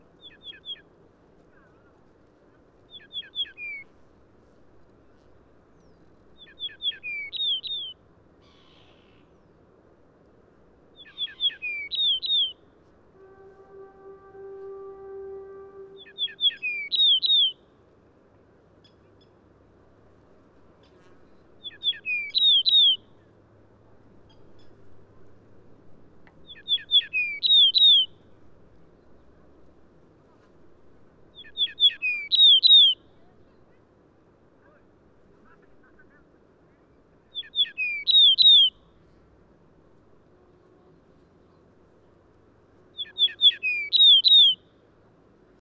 PFR09574, 2-10, 130610, Yellow-breasted Bunting Emberiza aureola aureola, song,
UB ponds, Mongolia